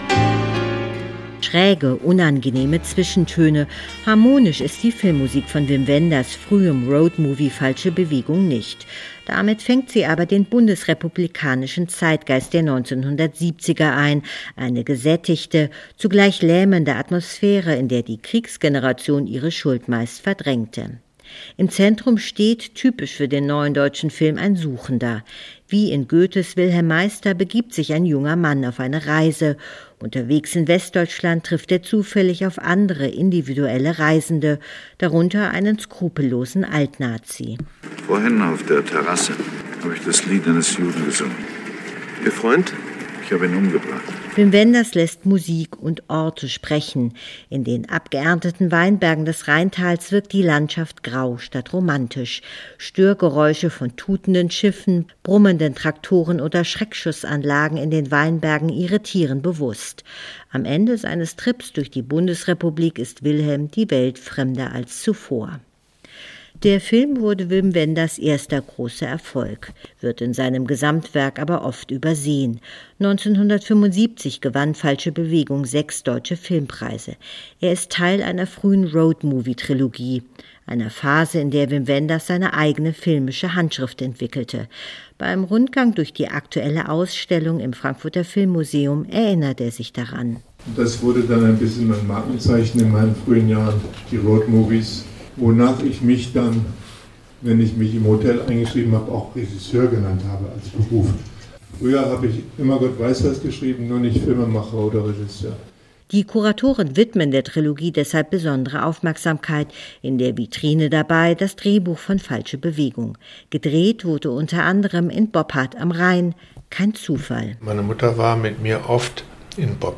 „Das wurde ein bisschen mein Markenzeichen in den ersten Jahren: die Roadmovies, wonach ich mich, wenn ich mich im Hotel eingeschrieben habe, Regisseur genannt habe“, sagt Wim Wenders beim Rundgang durch die nach ihm benannte Ausstellung im Frankfurter Filmmuseum.